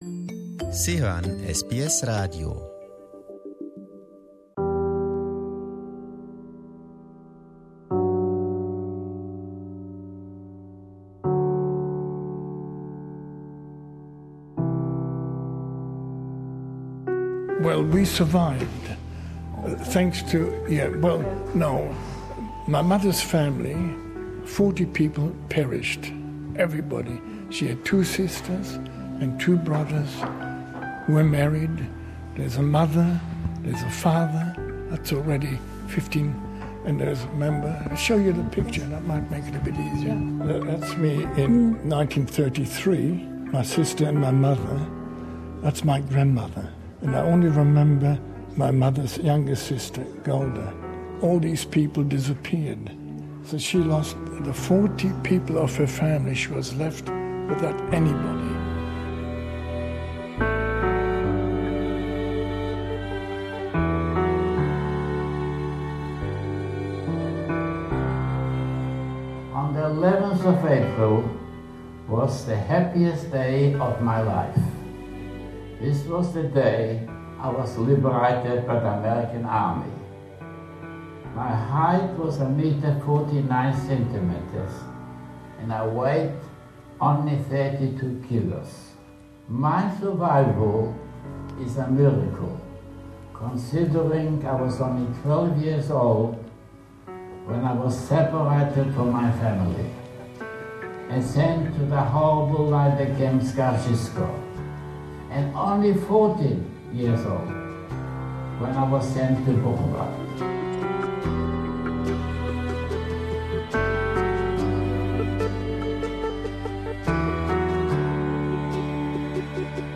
SBS accompanied Victorian high school students during their visit to Melbourne's Jewish Holocaust Centre, where they not only got the chance to get a deeper understanding of the historical events but also to speak to eyewitnesses, who still drop in at the Centre frequently.